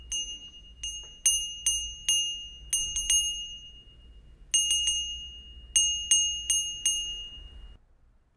bell call ding metal sound effect free sound royalty free Sound Effects